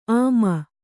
♪ āma